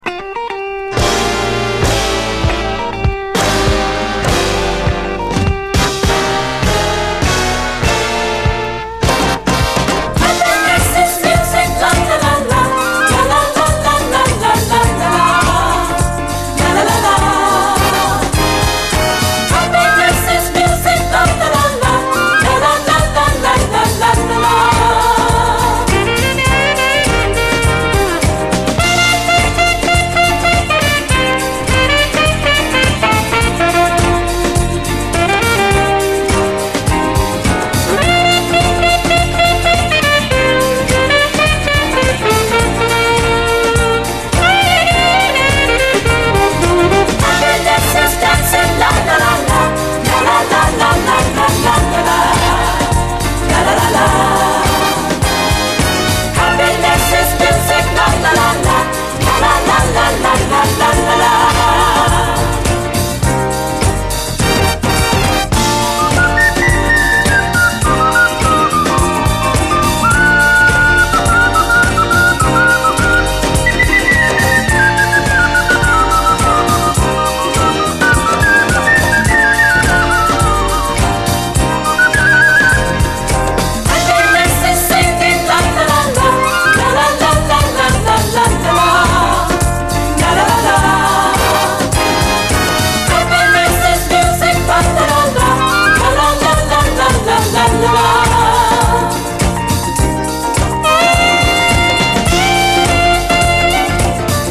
SOUL, 70's～ SOUL
脳天を突き抜けるようなコーラスのハッピー・ソウル